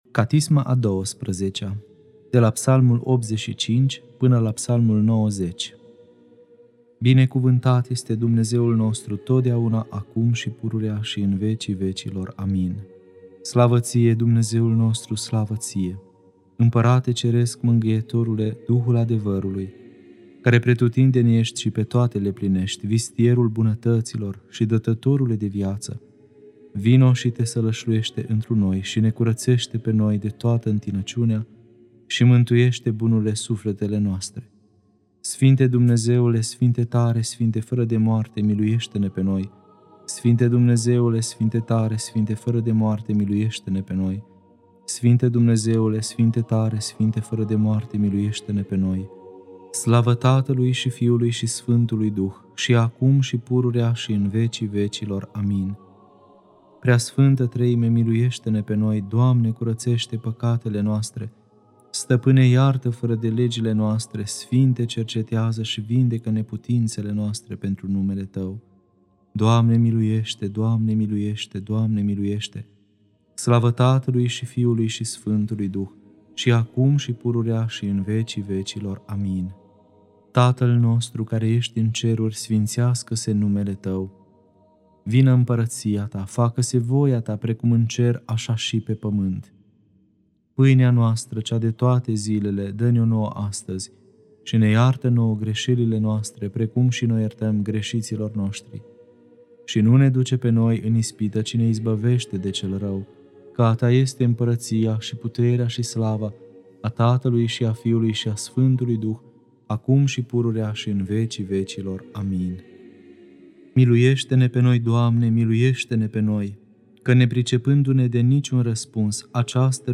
Catisma a XII-a (Psalmii 85-90) Lectura